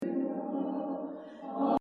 sur certaines fréquences on entend clairement une sorte de chorus ou flanging ! et les voix sont quand-même un peu dénaturées dans l'ensemble - en tout cas le fichier "choeur" (les voix toutes seule) contient pas mal d'artefacts et mauvaises résonances tout le long; et on l'entend très fortement sur les fins de phrase et à la respiration des chanteurs/euses (résonance genre boite de conserve)
et puis tu verras qu'une fois que tu as "repéré" les "colorations flanging" on les entend clairement tout le long de l'enregistrement... ces colorations sont dues aux déphasages
c'est sans doute dû à l'utilisation et emplacement de nombreux micros et leur différences de qualité (mais aussi dû en partie à la reverbe du lieu)